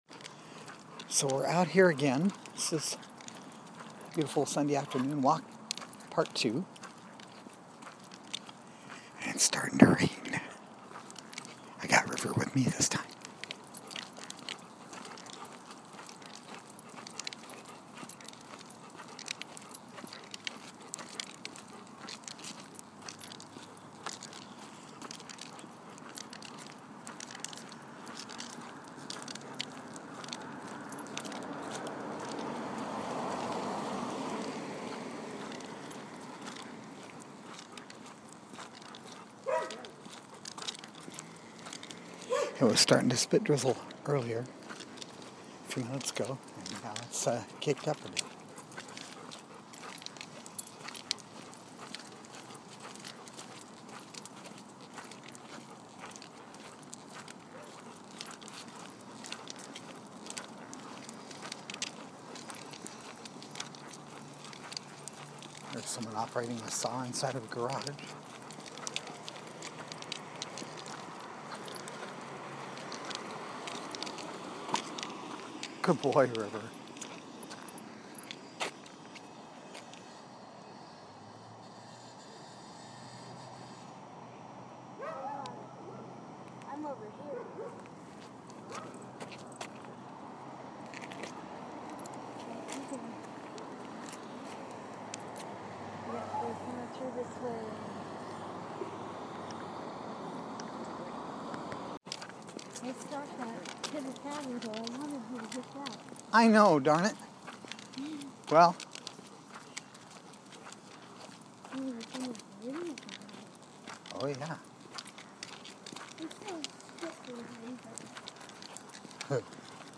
Walk on a beautiful Sunday afternoon part two, with a little rain